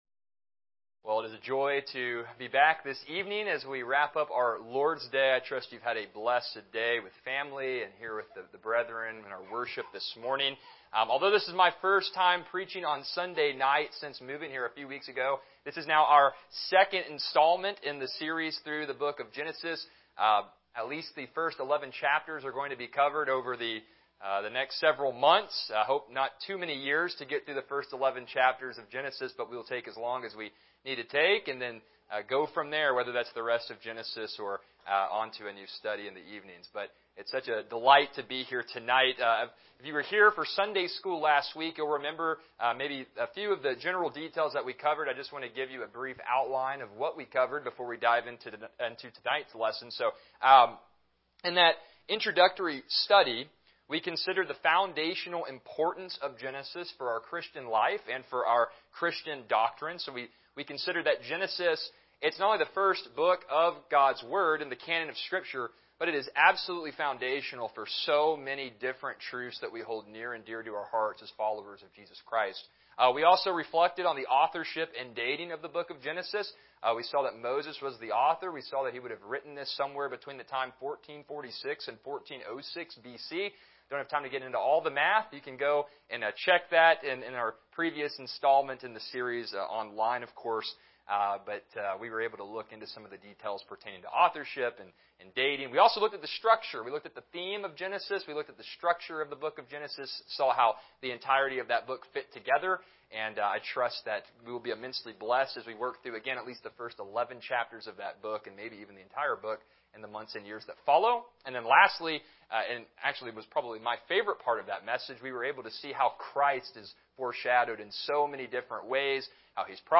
Service Type: Evening Worship